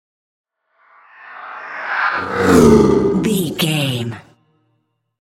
Creature whoosh horror
Sound Effects
Atonal
ominous
eerie
whoosh